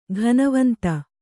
♪ ghanavanta